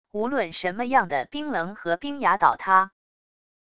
无论什么样的冰棱和冰崖倒塌wav音频